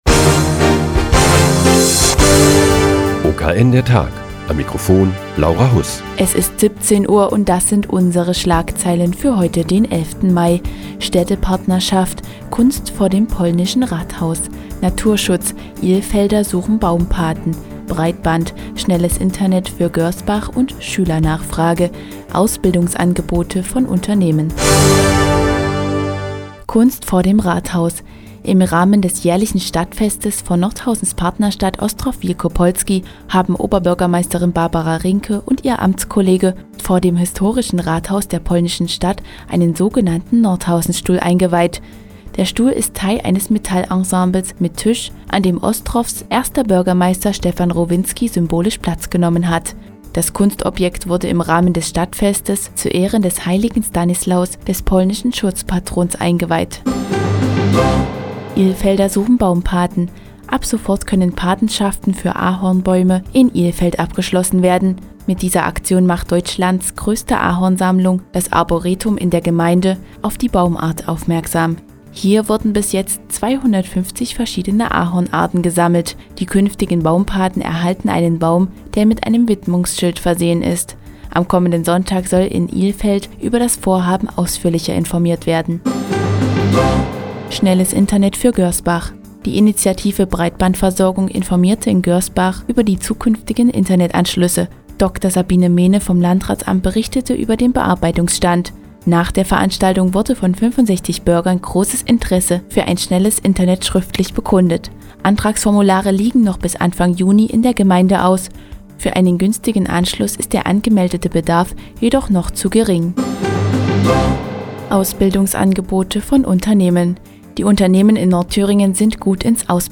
Die tägliche Nachrichtensendung des OKN ist nun auch in der nnz zu hören. Heute geht es um die Suche nach Baumpaten für Ahornbäume in Ilfeld und den guten Start ins Ausbildungsjahr 2010.